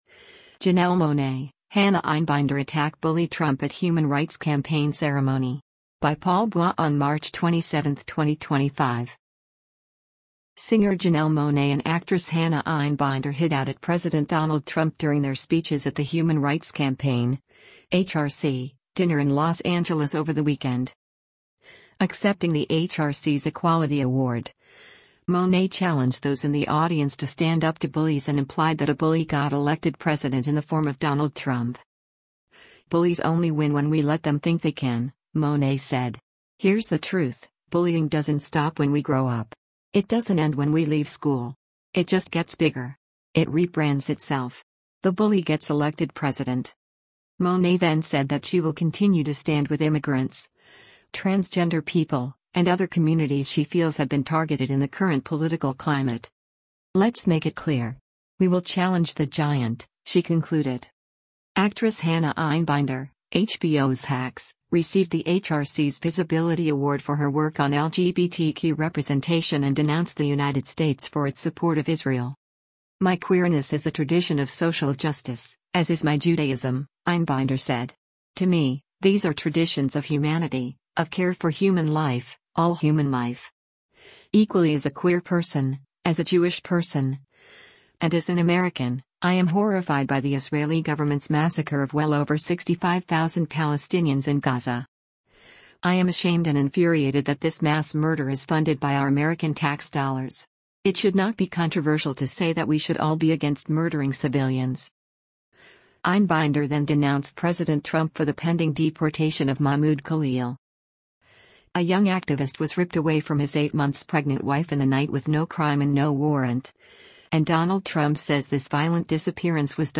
Singer Janelle Monáe and actress Hannah Einbinder hit out at President Donald Trump during their speeches at the Human Rights Campaign (HRC) dinner in Los Angeles over the weekend.